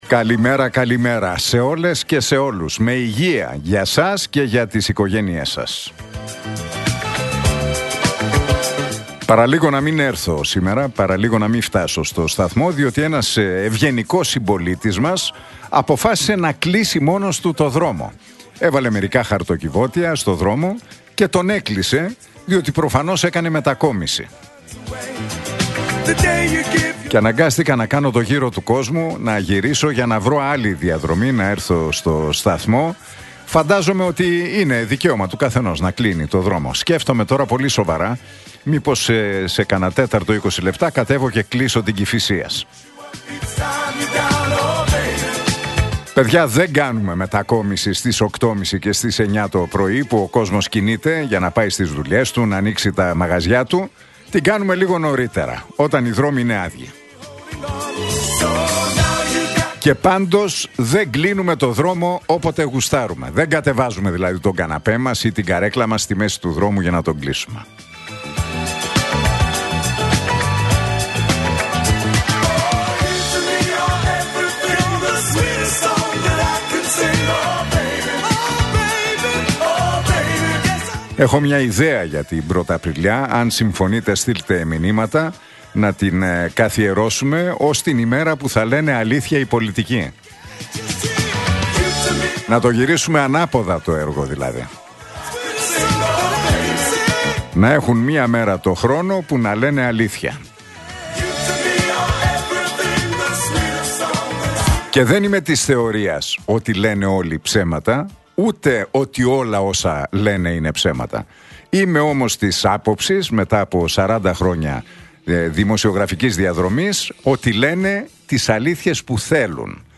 Ακούστε το σχόλιο του Νίκου Χατζηνικολάου στον ραδιοφωνικό σταθμό RealFm 97,8, την Τρίτη 1 Απριλίου 2025.